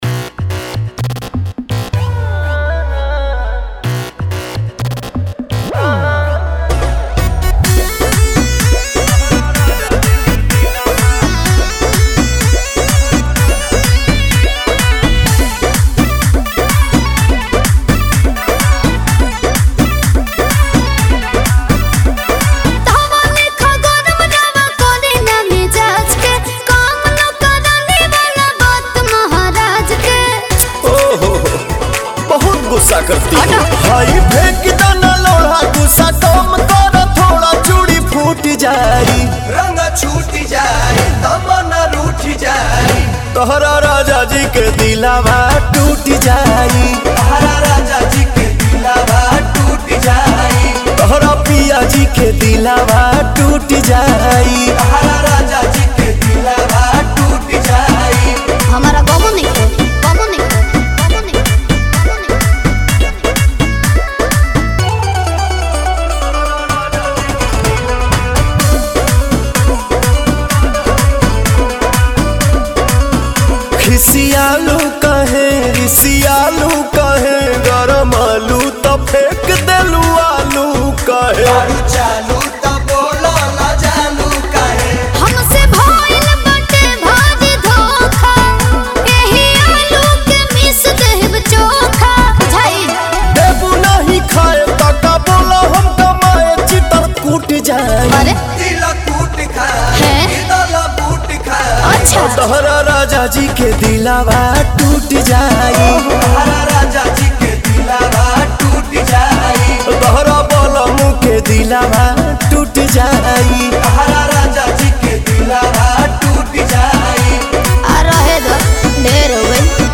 Dj Remix